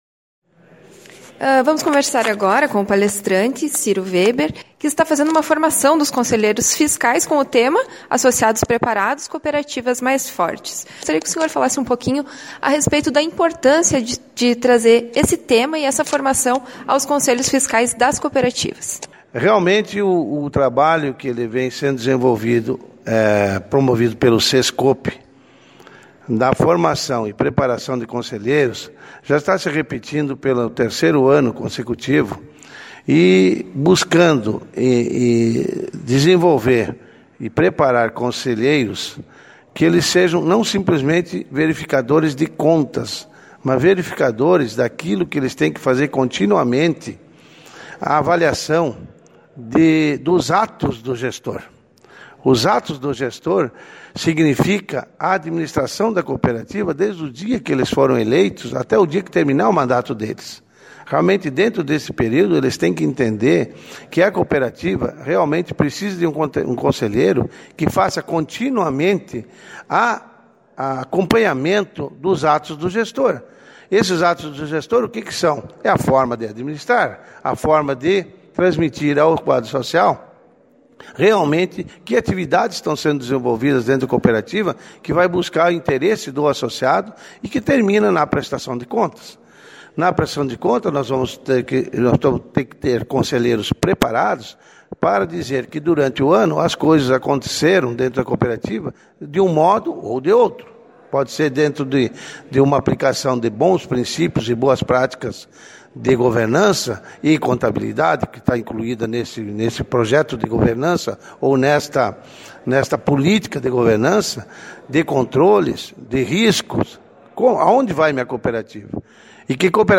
Nosso agradecimento à assessoria de imprensa da Cotrijal pela cedência das fotos e do áudio da entrevista